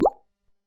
node-click.mp3